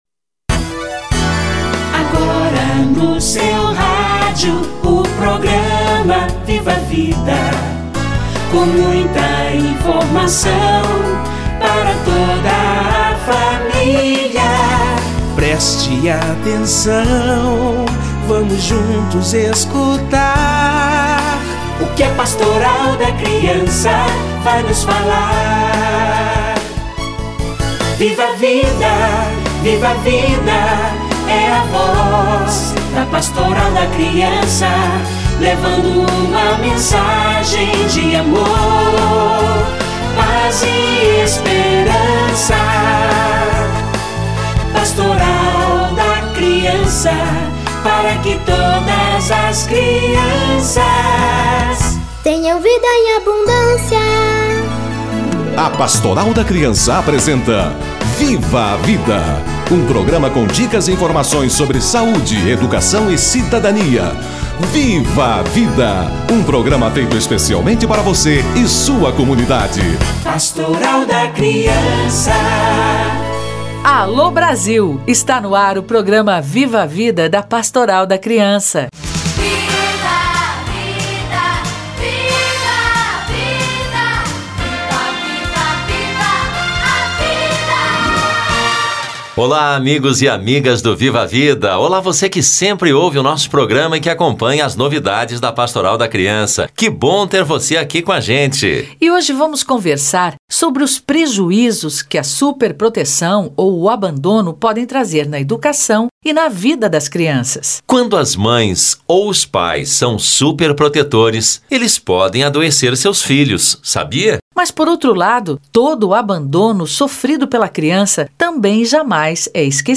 Abandono ou superproteção da criança - Entrevista